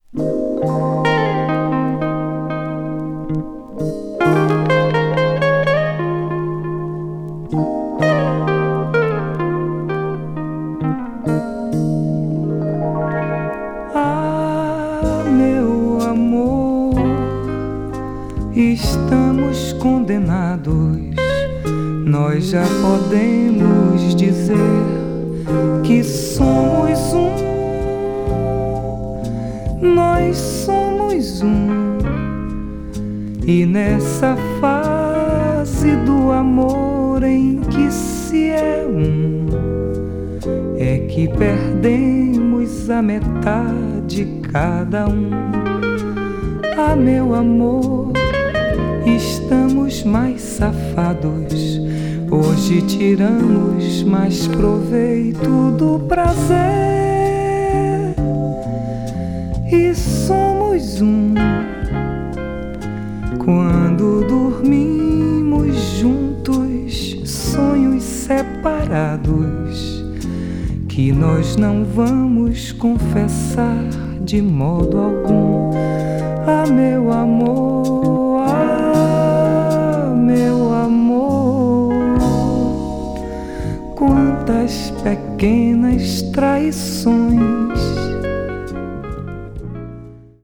boss nova   brazil   mellow groove   mpb   pop   world music